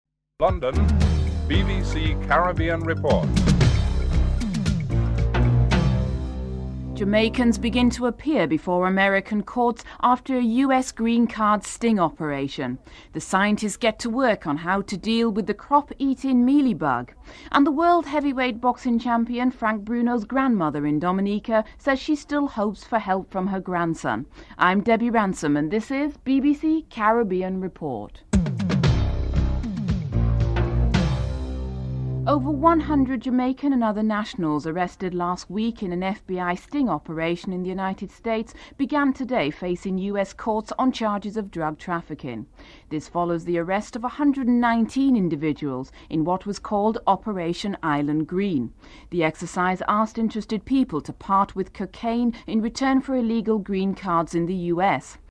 US Attorney Kendall Coffey explains the exercise that induced traffickers to part with cocaine in exchange for green cards.
St. Lucia's Prime Minister John Compton expresses concern about the recent upheavals in the banana industry in the Windward Islands.